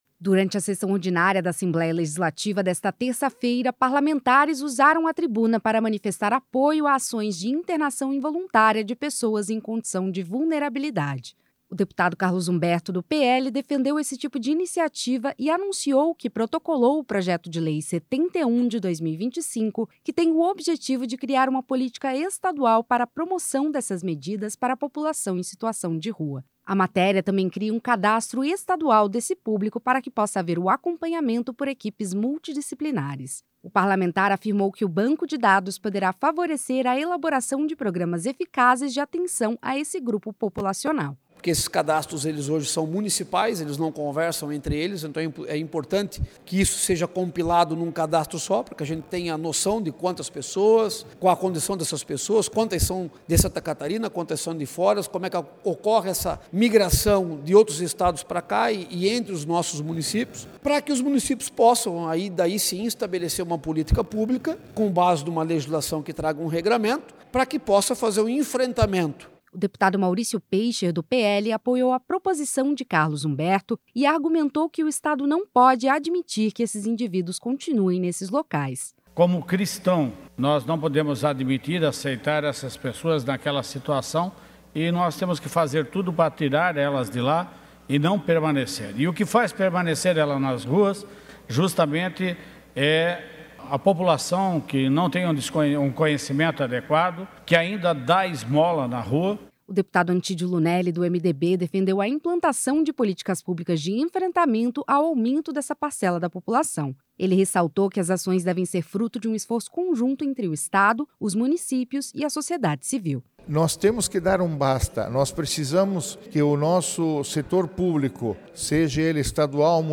Entrevistas com:
- deputado Carlos Humberto (PL), autor do PL 71/2025;
- deputado Maurício Peixer (PL);